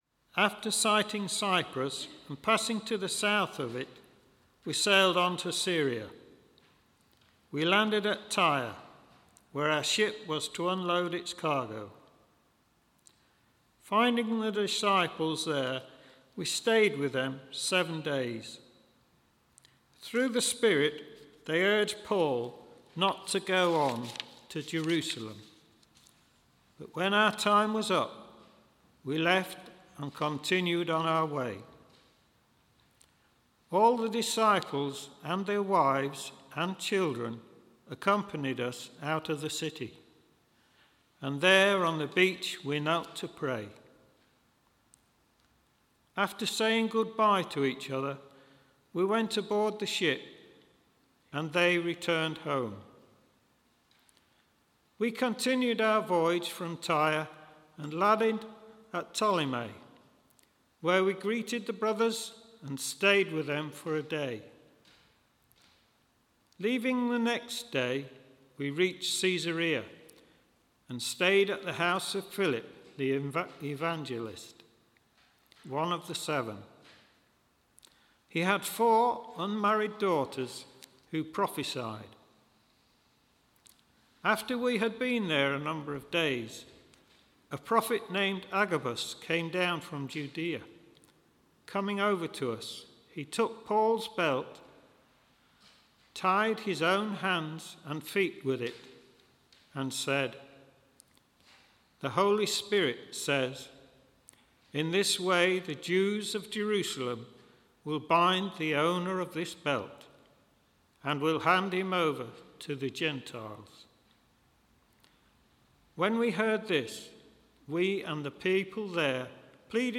EGU Louth sermon: Acts 21: Discerning the will of God